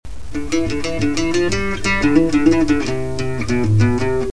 Intro: Dm, C, Dm, F, G7, C
Requinto